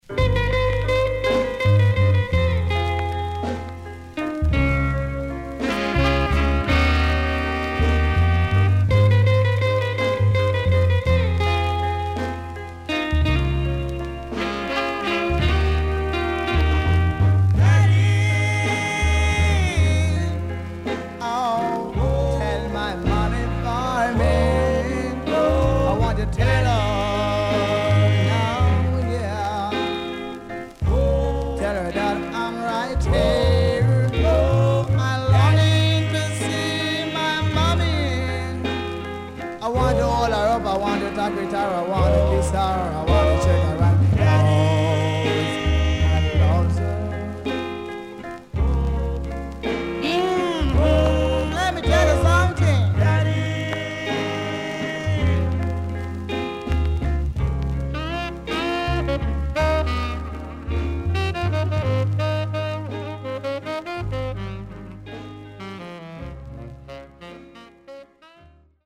CONDITION SIDE A:VG(OK)
Nice Ska Vocal & Ballad
SIDE A:全体的にチリノイズがあり、少しプチノイズ入ります。